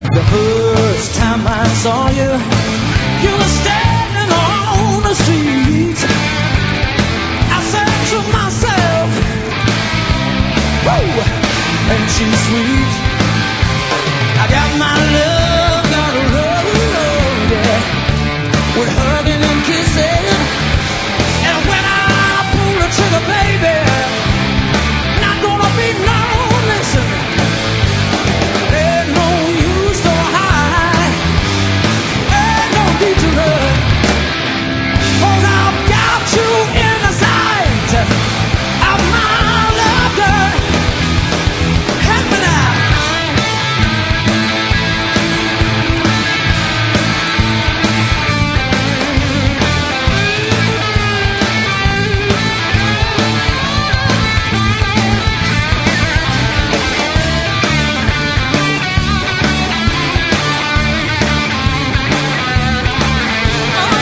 sur scène à Woodstock en 1994